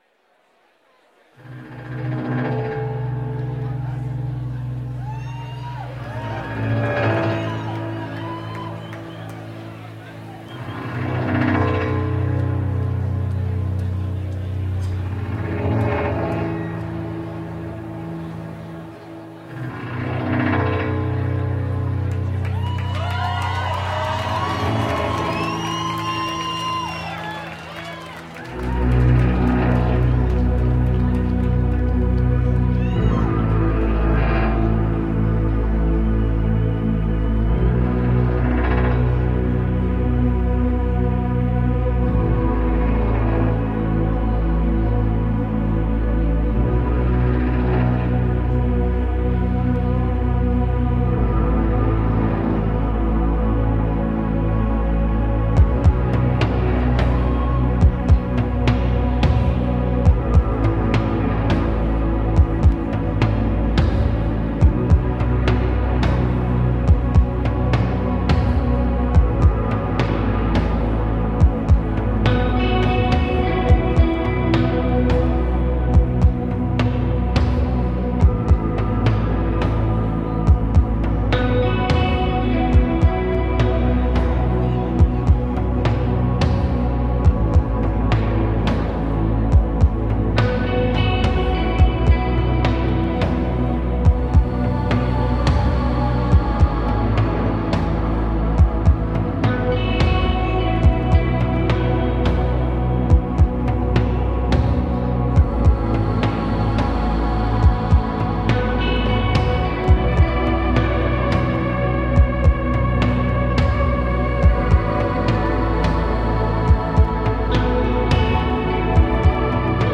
live at Paradise Rock Club, Boston
in concert at Paradise Rock Club
electronic music